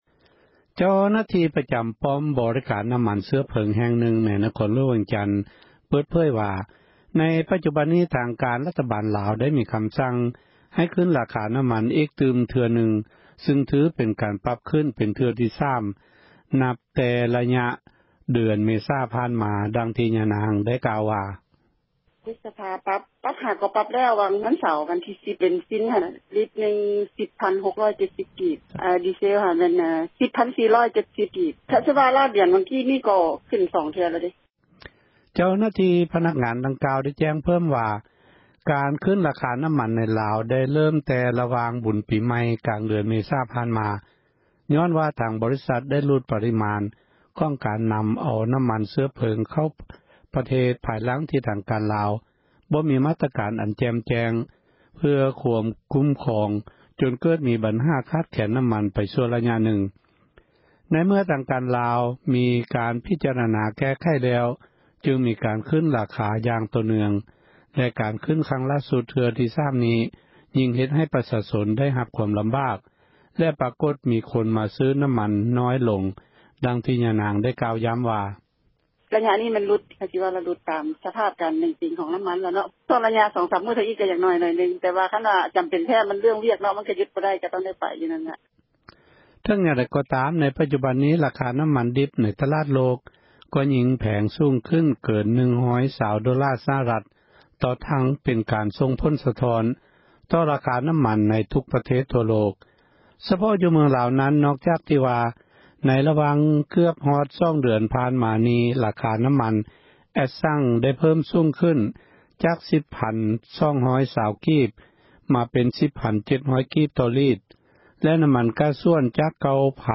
ສຽງນ້ຳມັນຂຶ້ນລາຄາ